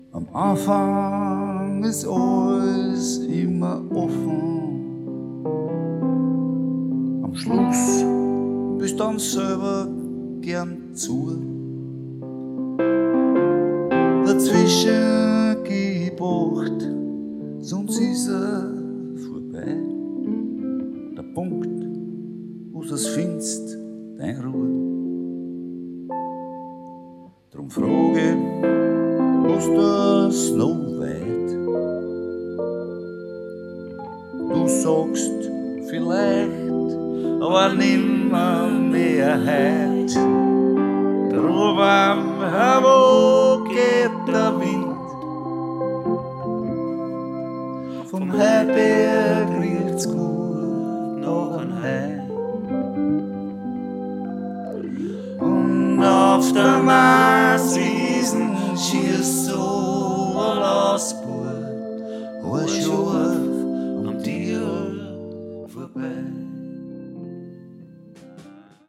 Gesang, Mundharmonika, Percussion